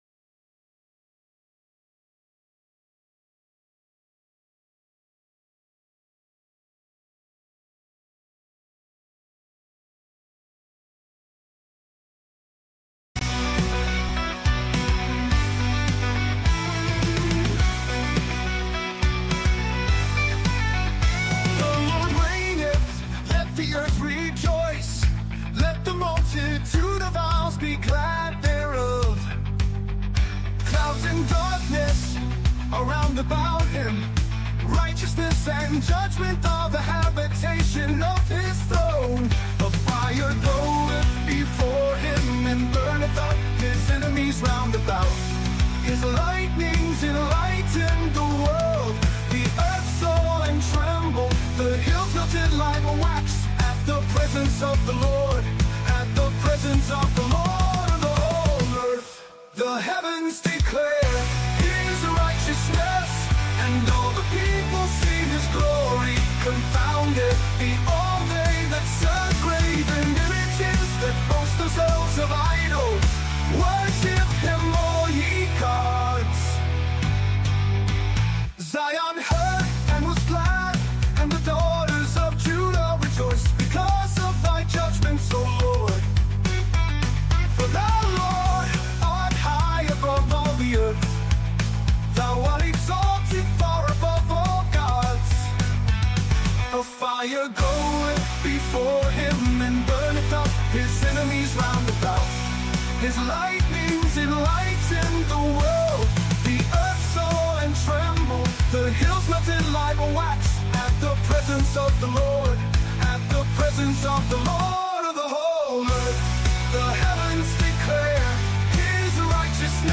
Talk Show Episode, Audio Podcast, Sons of Liberty Radio and Nurses All Over The World Facing Summer Of Died Suddenly on , show guests , about Nurses All Over The World Facing Summer Of Died Suddenly, categorized as Education,History,Military,News,Politics & Government,Religion,Christianity,Society and Culture,Theory & Conspiracy